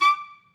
Clarinet
DCClar_stac_D5_v3_rr1_sum.wav